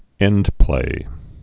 (ĕndplā)